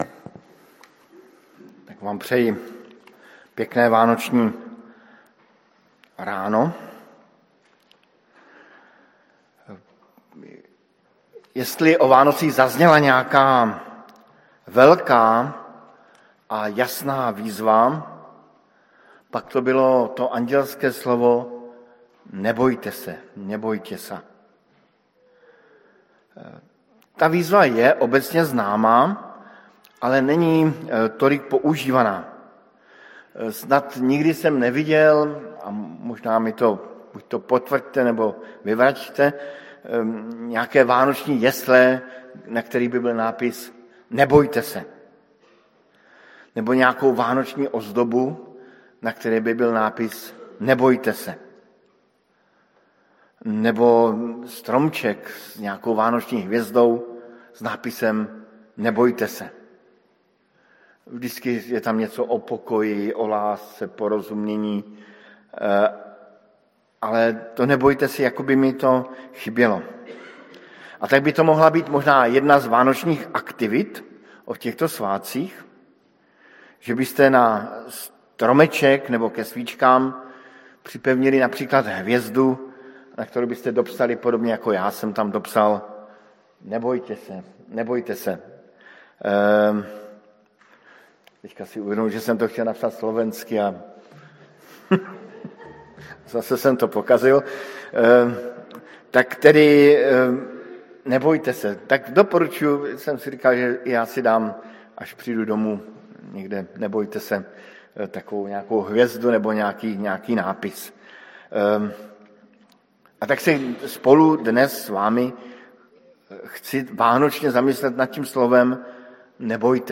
Kázeň